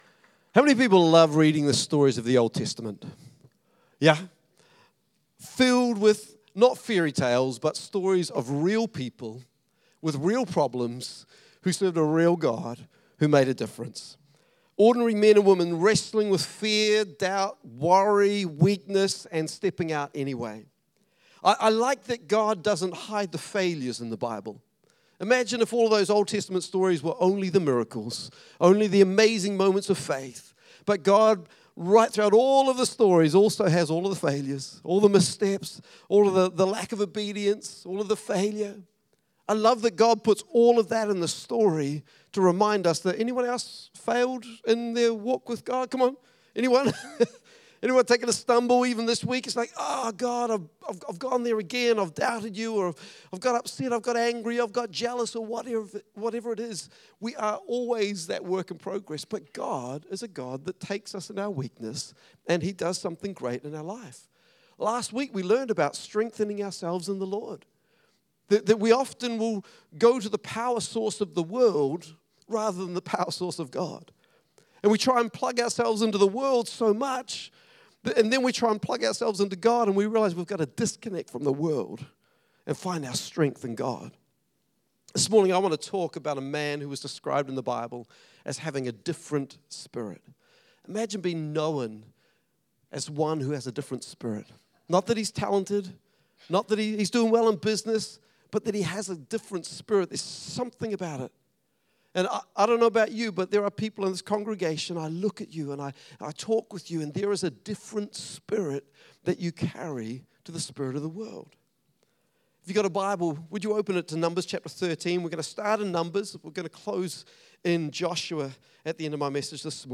[11:30am Service]
Sunday Messages